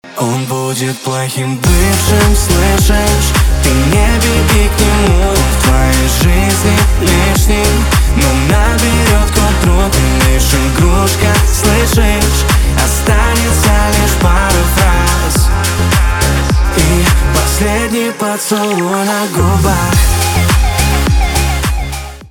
поп
грустные
битовые , печальные , качающие